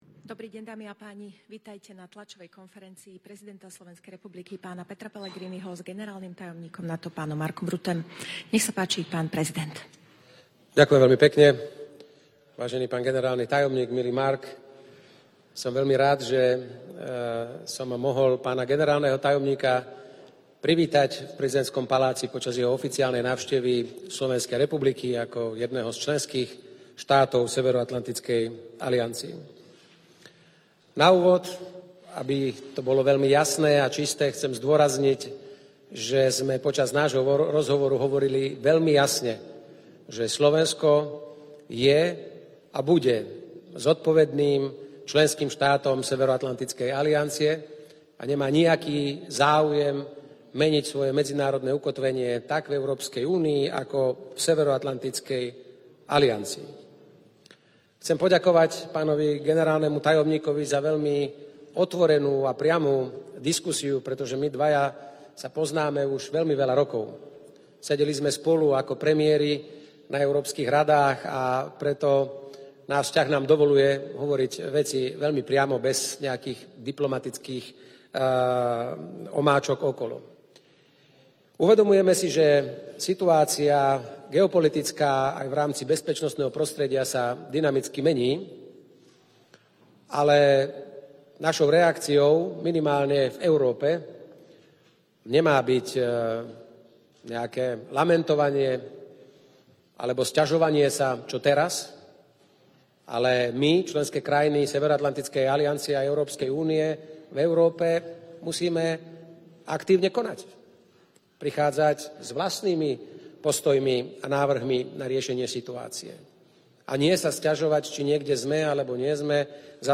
Joint press conference
by NATO Secretary General Mark Rutte with the President of Slovakia Peter Pellegrini